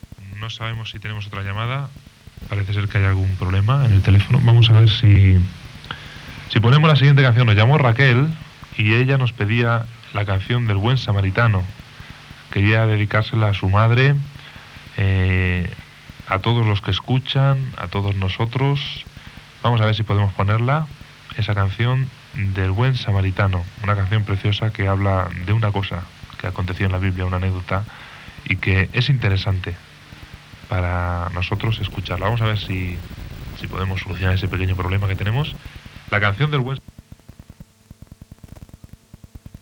Moment de la clausura de les instal·lacions.